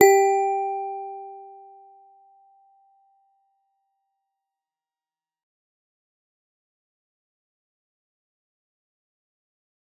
G_Musicbox-G4-f.wav